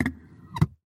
Настройка телескопической опоры